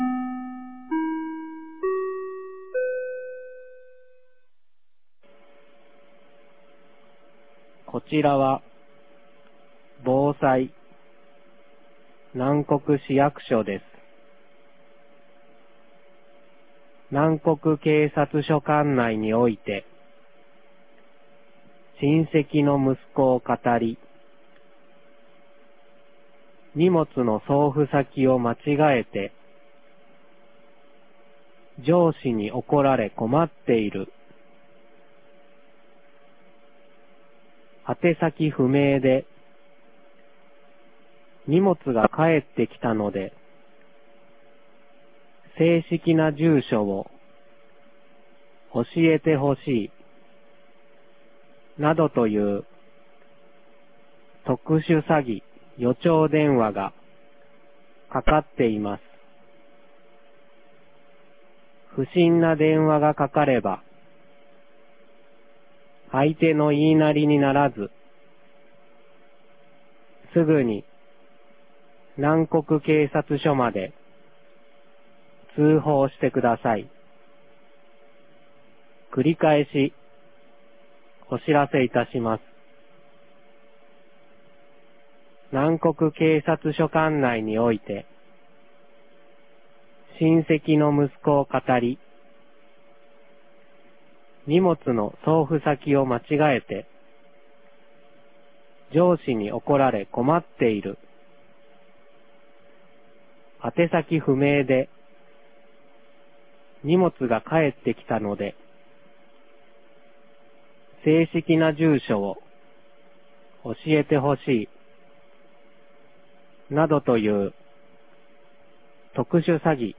2022年10月25日 16時17分に、南国市より放送がありました。